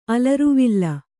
♪ alaruvilla